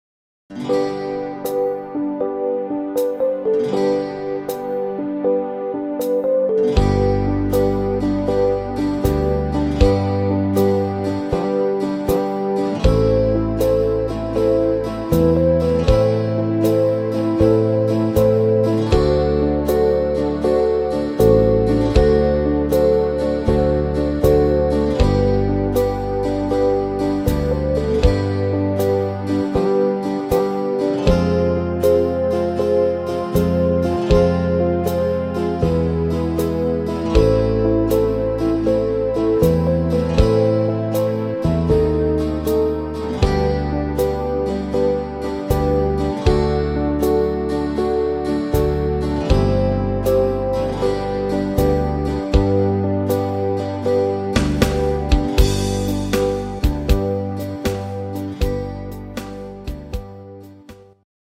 Rhythmus  Slowwaltz
Art  Instrumental Gitarre, Oldies